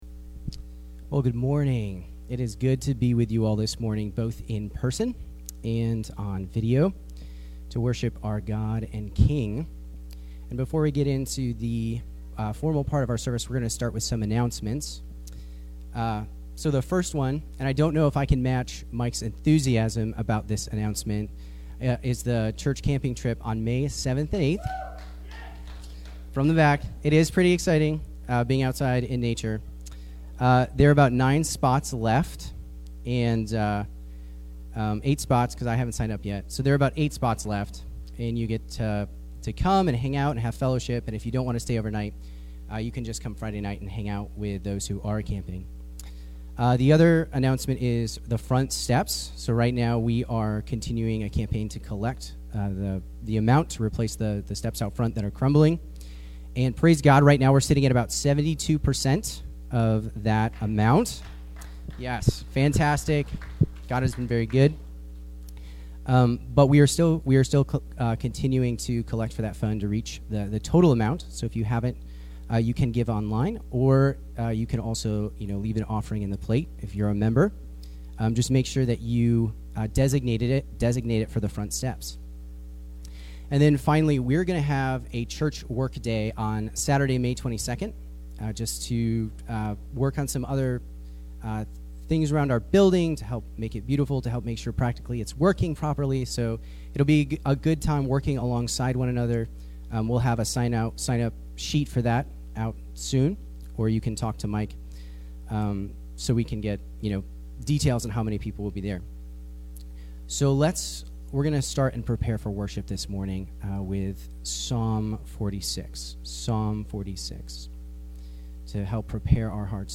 April 18 Worship Audio – Full Service